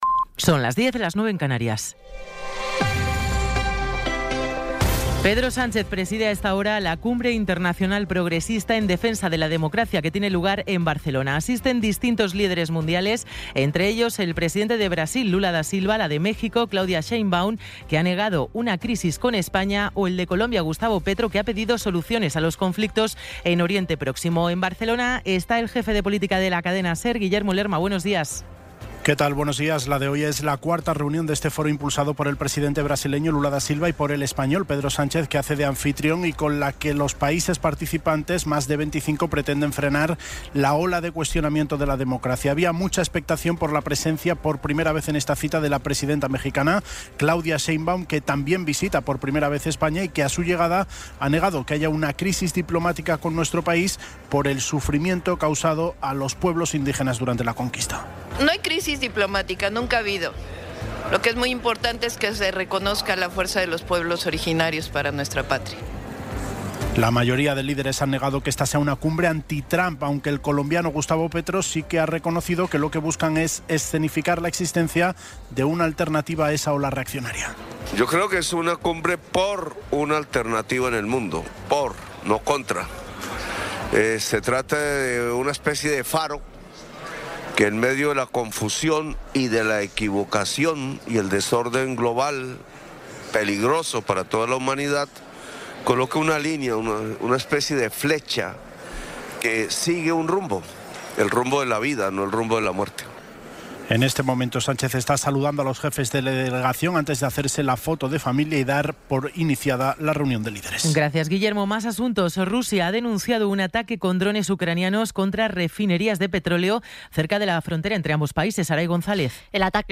Resumen informativo con las noticias más destacadas del 18 de abril de 2026 a las diez de la mañana.